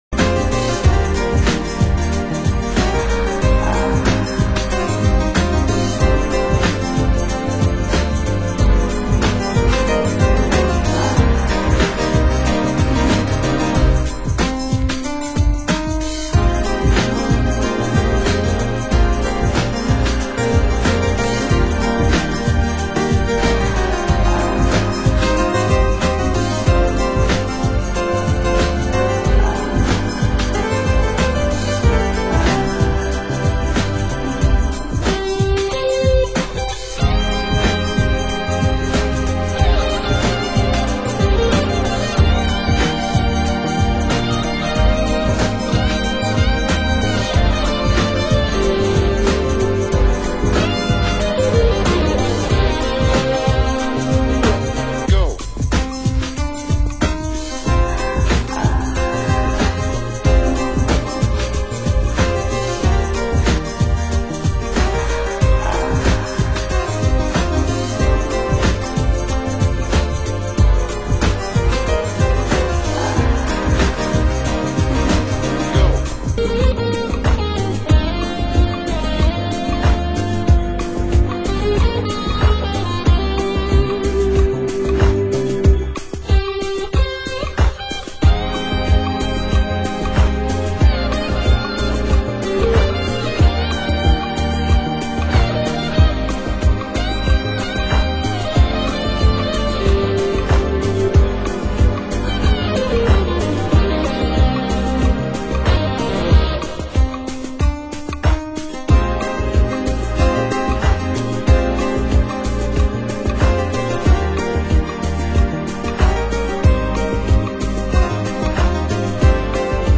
Genre: Euro House
club mix